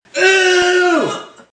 Tags: Jeapordy Game Show Sounds Effect